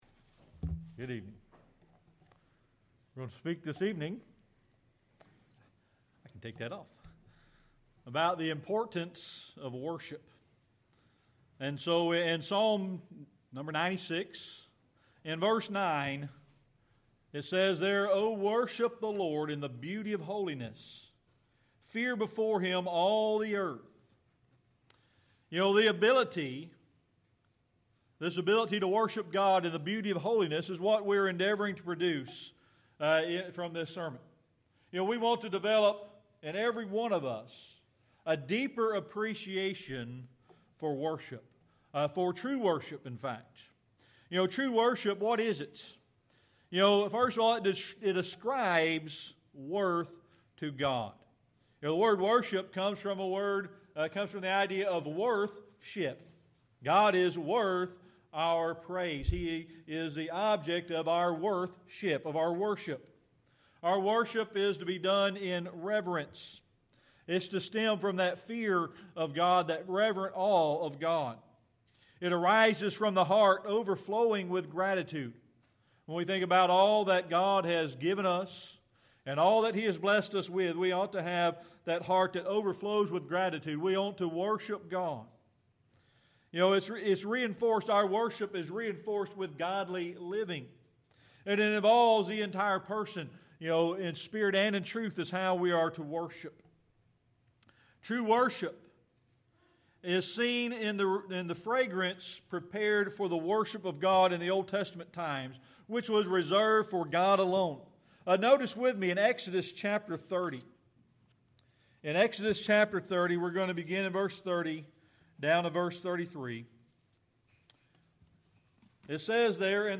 Series: Sermon Archives